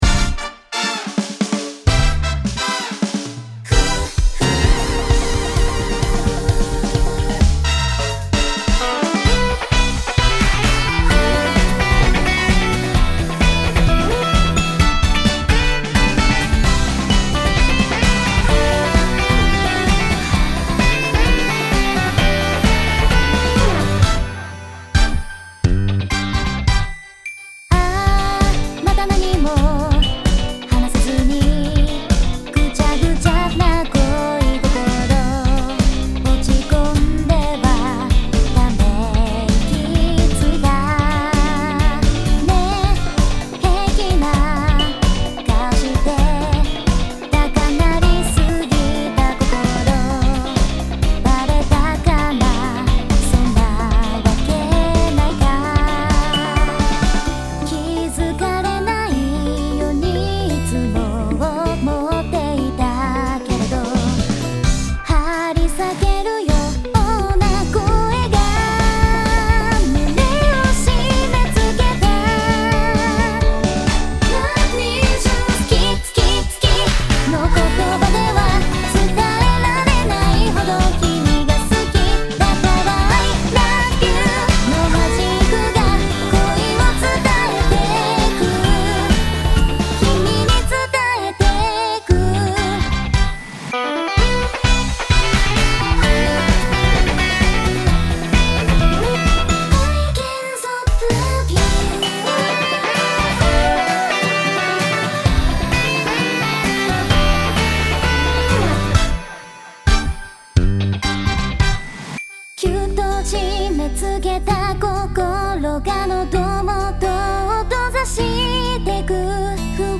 エレキギター
ドラム
エレキベース